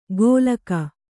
♪ gōlaka